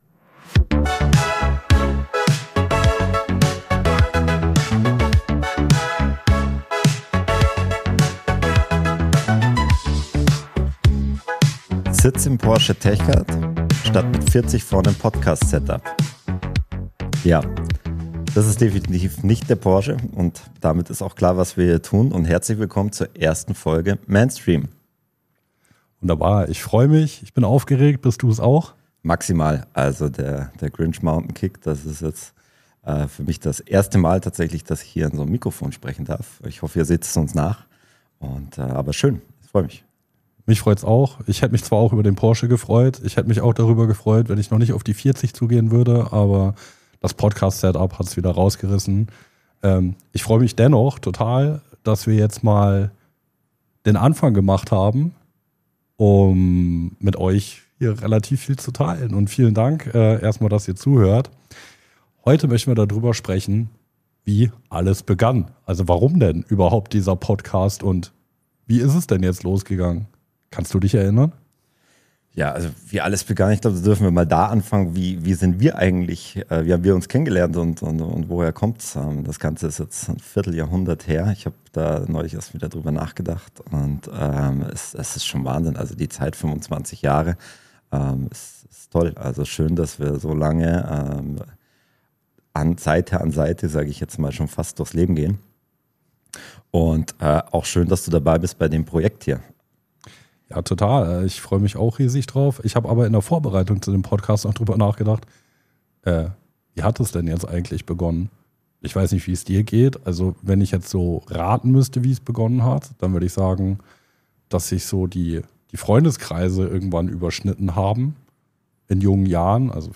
Zwei Männer, 25 Jahre Freundschaft – jetzt reden sie endlich über das, was sonst liegenbleibt. MENSTREAM startet da, wo Smalltalk endet: bei Familie, Druck, Zielen und all dem, was man zwischen Karriere und Kinderchaos jongliert.